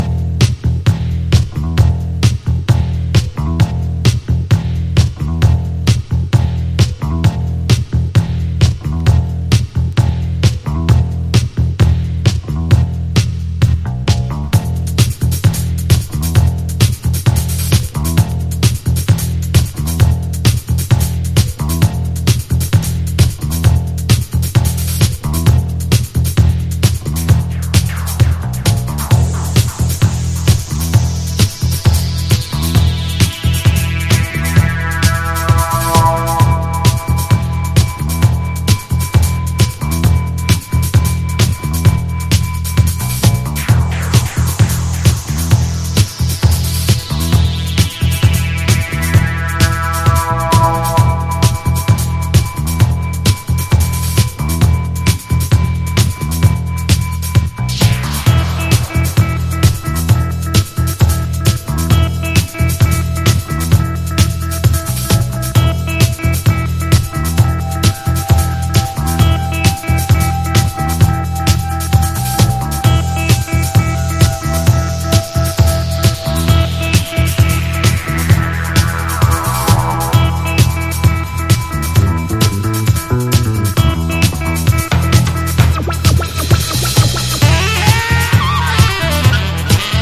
# DEEP HOUSE / EARLY HOUSE# NU-DISCO / RE-EDIT